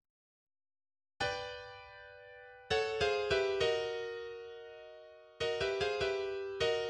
After a silent pause, the allegro risoluto section, much of it triple forte, is vigorous and brisk, and the ensuing second subject, dominated by the wind and brass, is no less so (evoking "Hampstead Heath on an August Bank Holiday") [4]